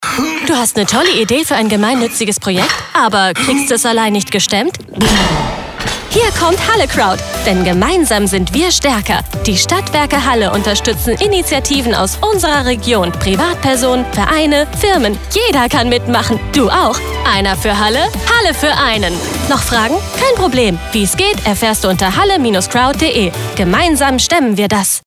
Professioneller Sprecher und Sänger.
Lustig
Reizvoll
Hell